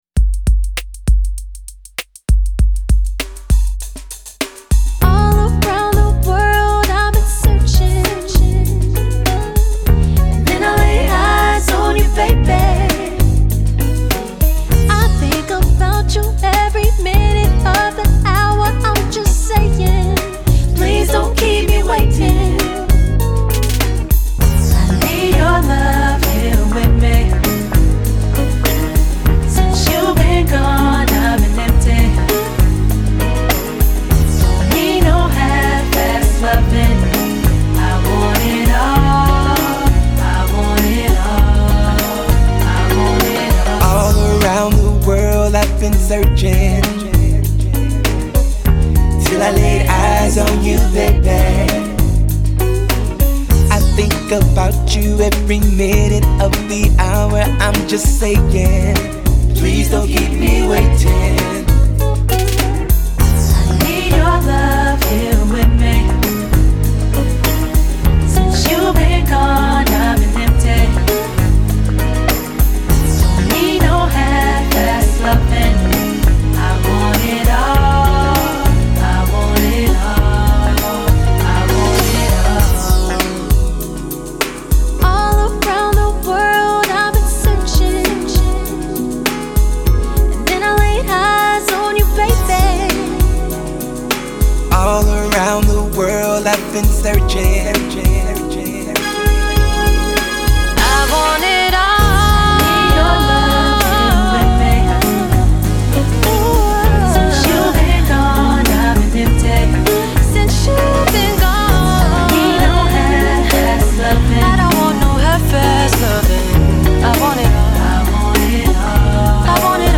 Genre : Funk